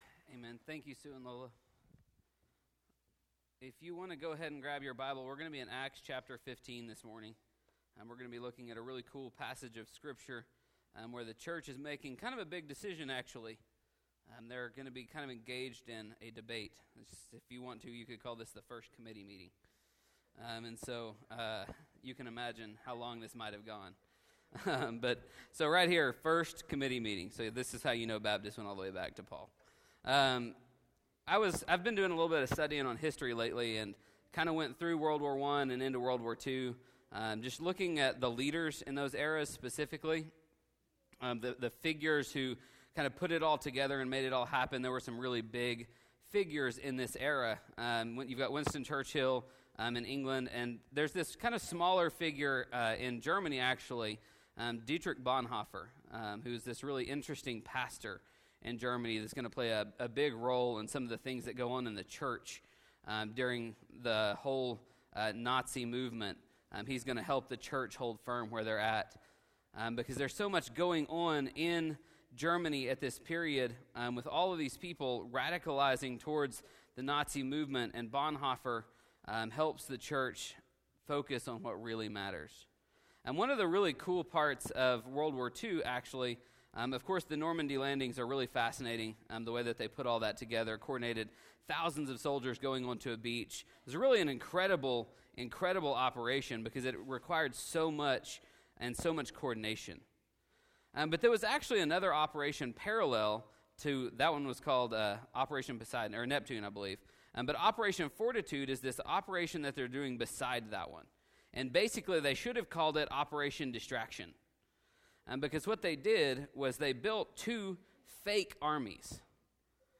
Last week we learned that our identity should be defined by our faith in Jesus Christ. The 4 main points of the sermon were: